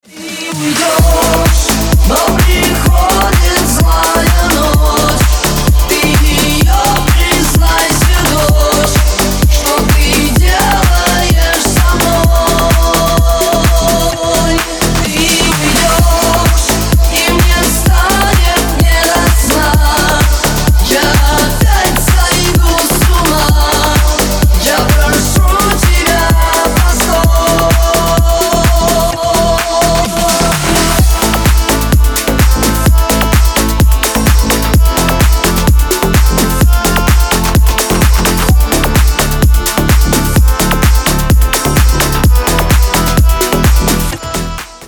Рингтоны ремиксов